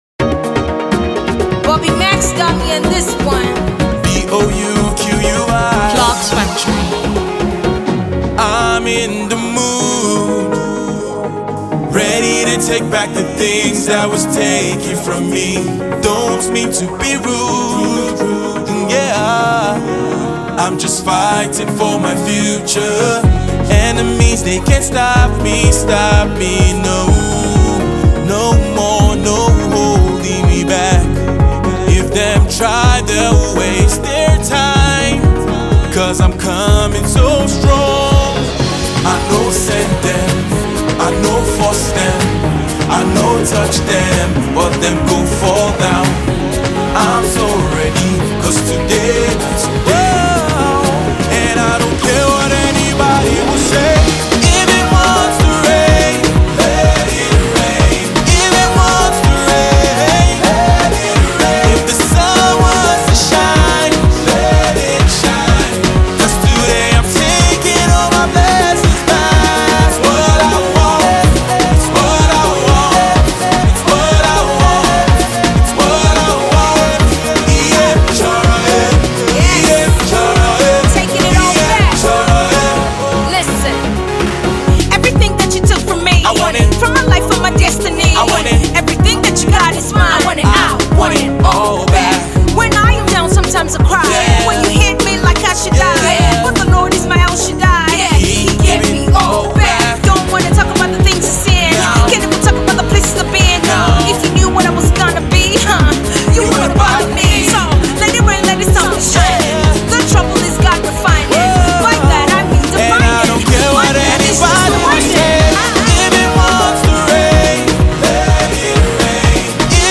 an inspirational and uplifting single